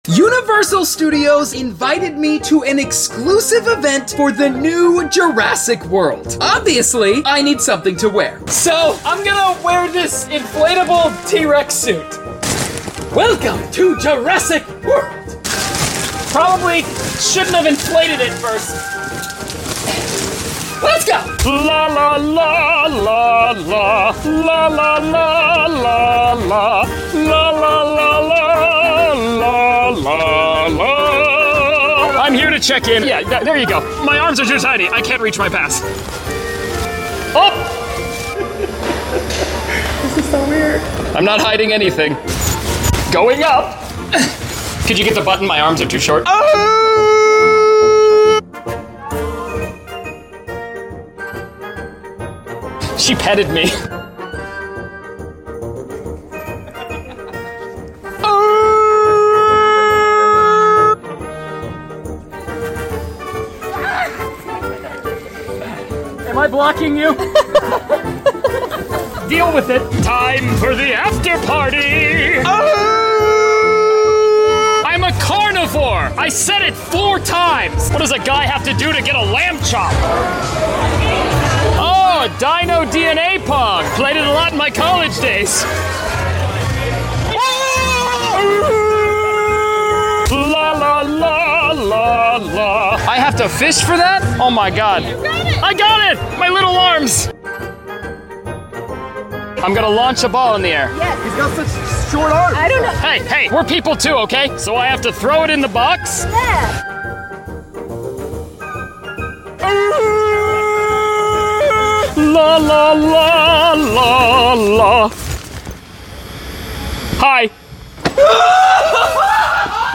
*Dino sound effects included* 🍿🦖 sound effects free download